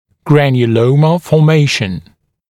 [ˌgrænjuˈləumə fɔː’meɪʃ(ə)n][ˌгрэнйуˈлоумэ фо:’мэйш(э)н]формирование гранулемы